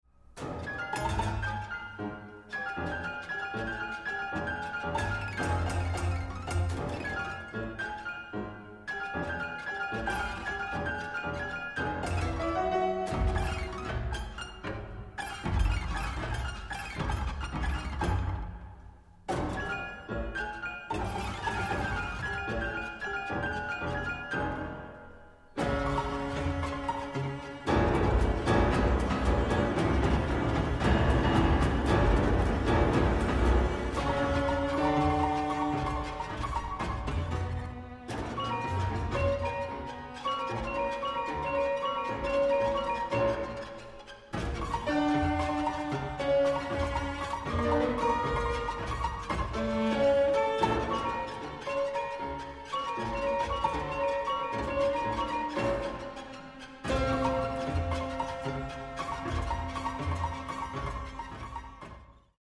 for 2 pianos, strings and computer